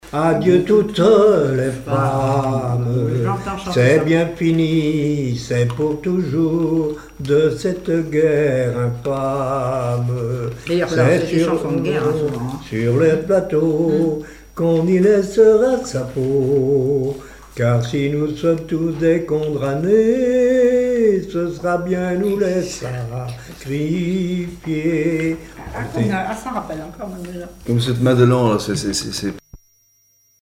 chanson de guerre
Genre strophique
Témoignages et chansons
Pièce musicale inédite